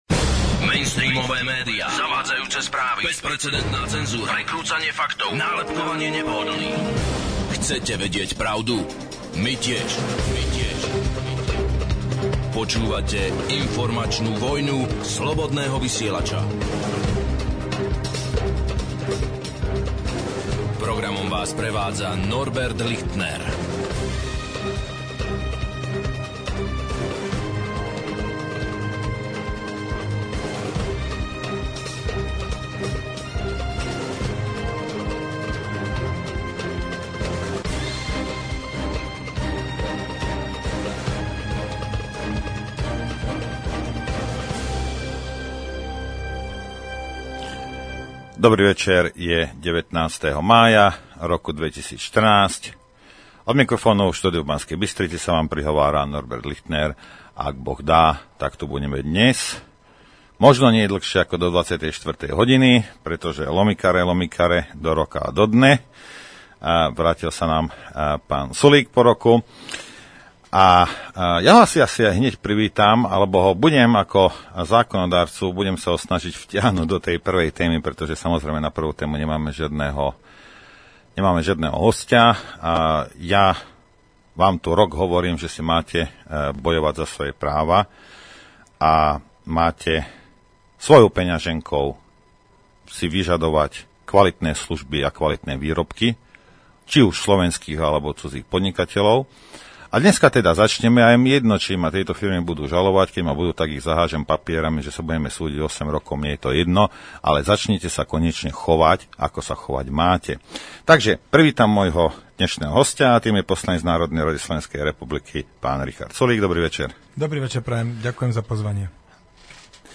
Superštát vs. samostatnosť. Hosť: Richard Sulík, poslanec NRSR